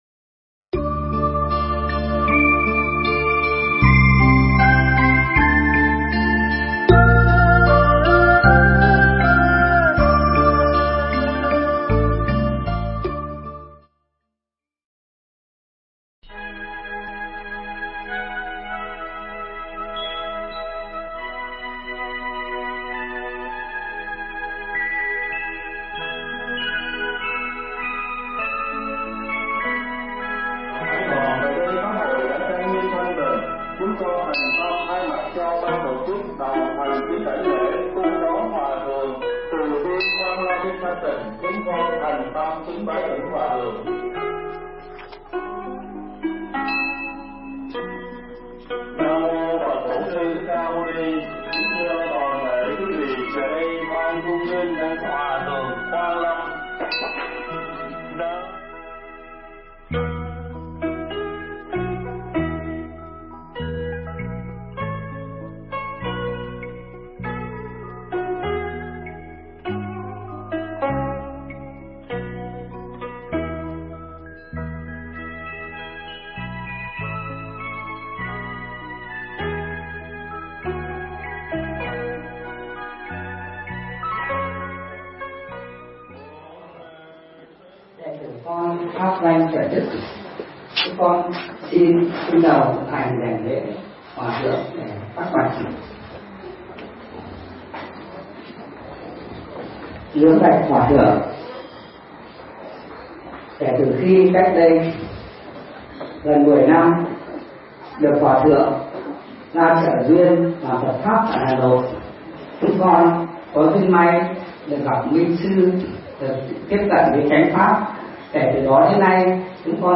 Tải mp3 thuyết pháp Đạo Phật – thầy Thích Thanh Từ
Mp3 Pháp Thoại Đạo Phật – Thầy Thích Thanh Từ nói chuyện với Đoàn Bác Sĩ - Hà Nội tại Sùng Phúc Thiên Tự ngày 6 tháng 3 năm 2005 (ngày 26 tháng 1 năm Ất Dậu)